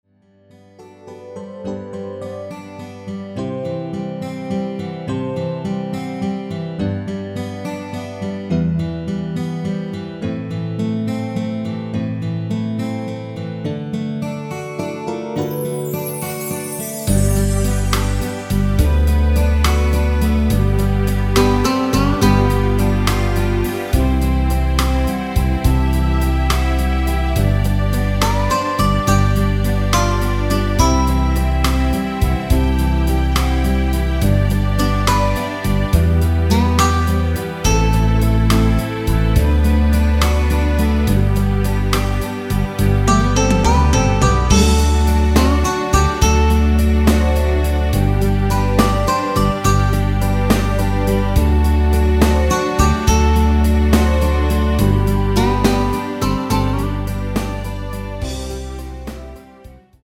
멜로디 MR이라고 합니다.
앞부분30초, 뒷부분30초씩 편집해서 올려 드리고 있습니다.